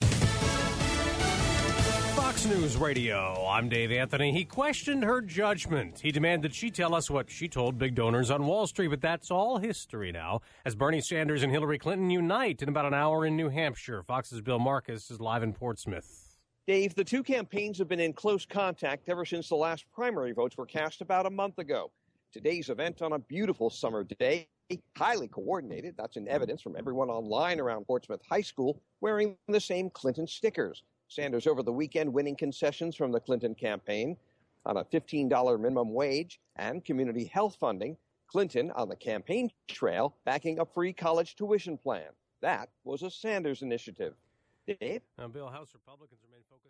(FOX NEWS RADIO, JULY 12, 10AM, LIVE) –
FOX-NEWS-RADIO-10AM-LIVE-12-JULY.mp3